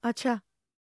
TALK 5.wav